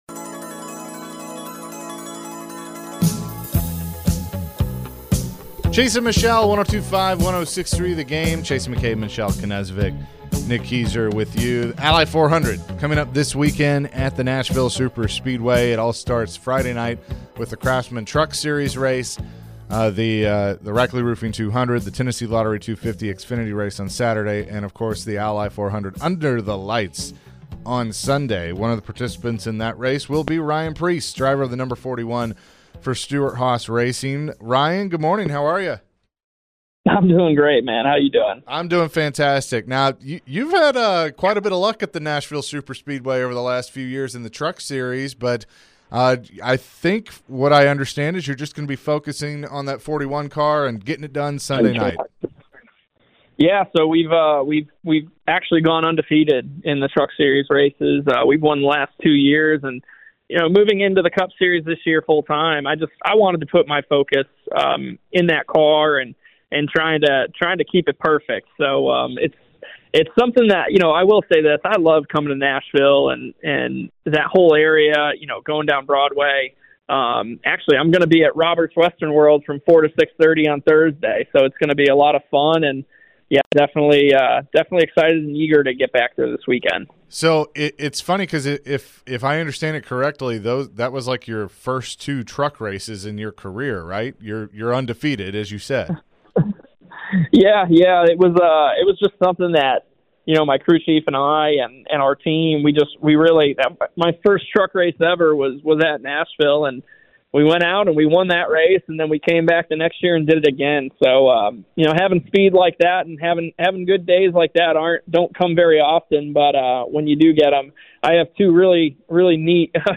Ryan Preece Interview (6-19-23)